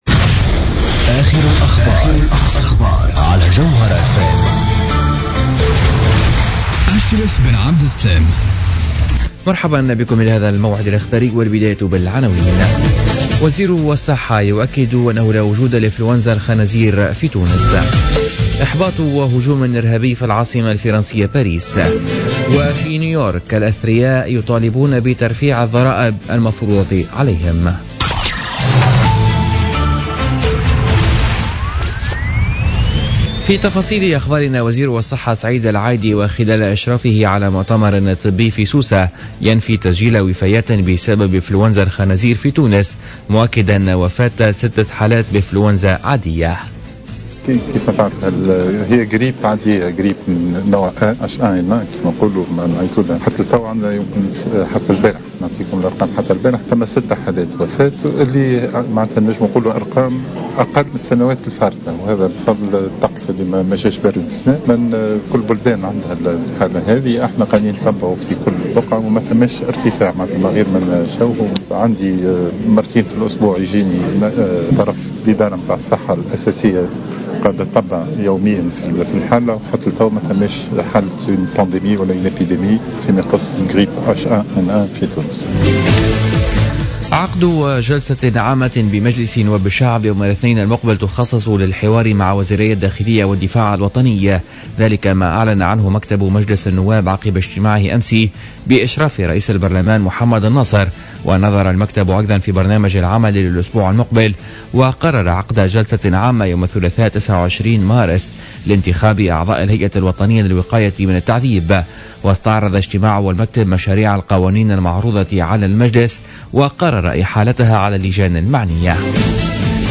نشرة أخبار منتصف الليل ليوم الجمعة25 مارس 2016